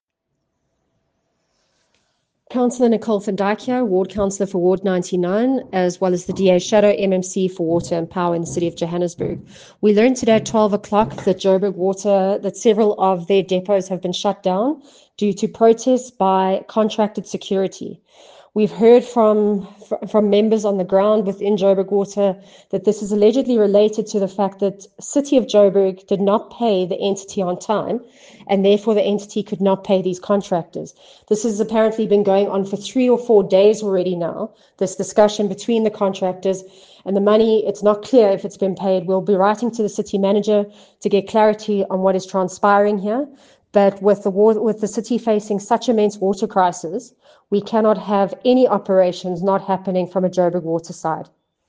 Note to Editors: Please find a soundbite by Cllr Nicole van Dyk